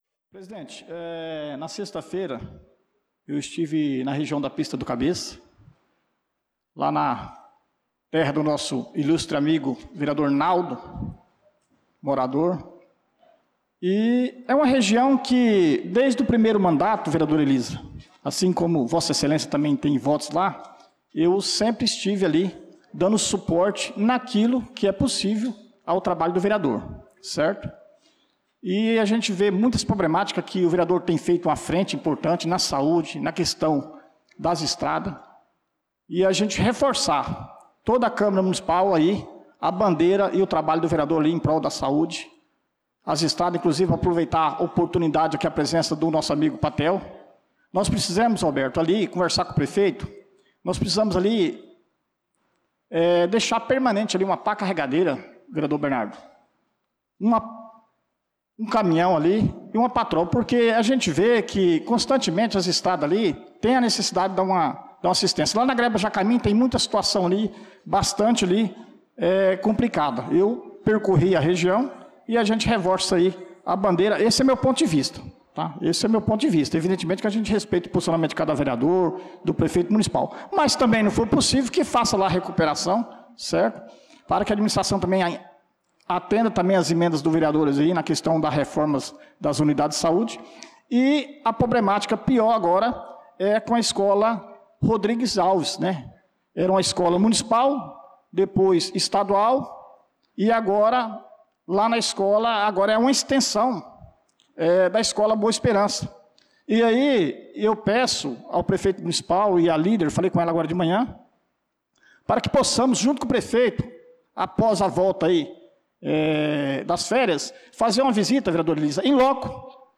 Pronunciamento do vereador Dida Pires na Sessão Ordinária do dia 07/07/2025.